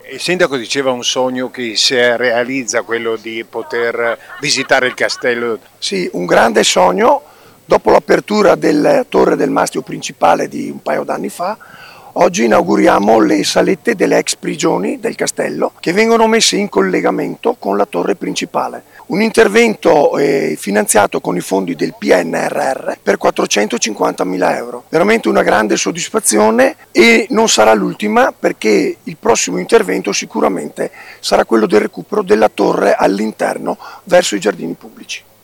Francesco Arduini, Vicesindaco e Assessore ai Lavori Pubblici
Francesco-Arduini-vicesindaco-e-assessore-ai-lavori-pubblici-di-Villafranca-di-Verona.mp3